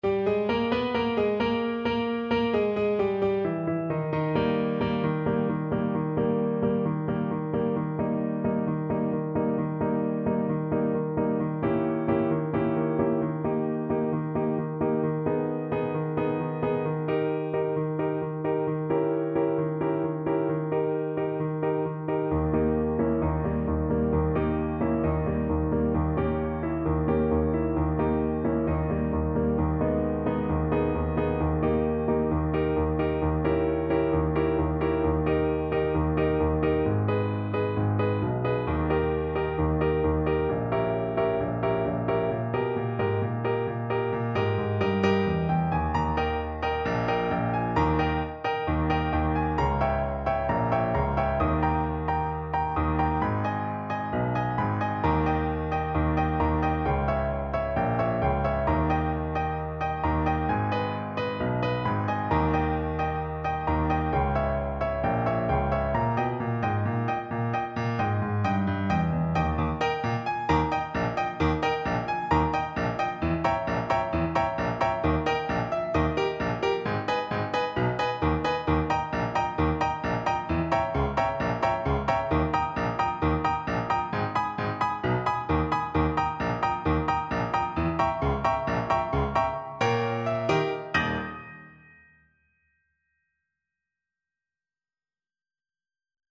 Trombone
Eb major (Sounding Pitch) (View more Eb major Music for Trombone )
Molto allegro =c.132
4/4 (View more 4/4 Music)
Traditional (View more Traditional Trombone Music)
world (View more world Trombone Music)